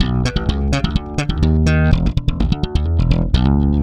Index of /90_sSampleCDs/Best Service ProSamples vol.48 - Disco Fever [AKAI] 1CD/Partition D/BASS-SLAPPED